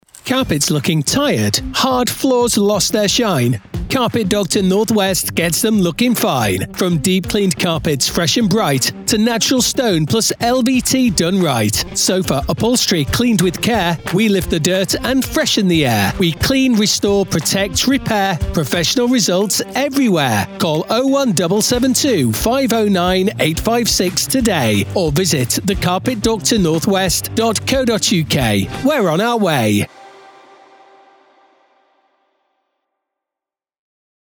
We're on the radio!